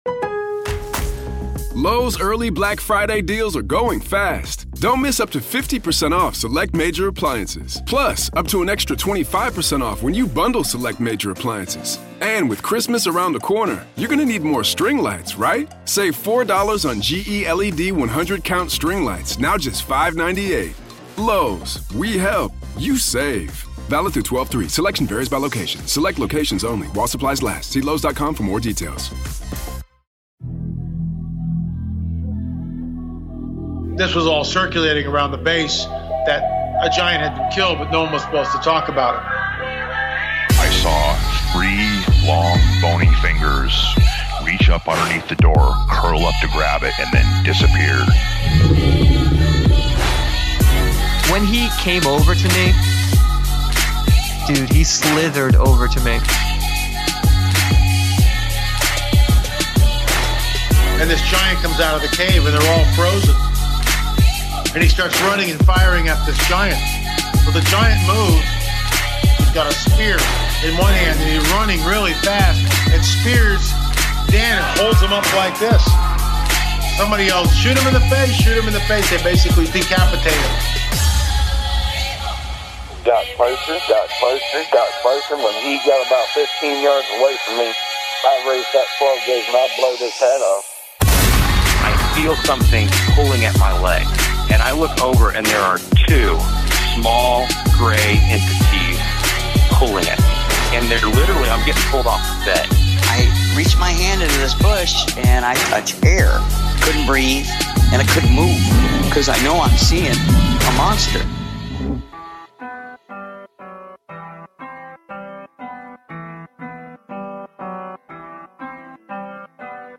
an exclusive interview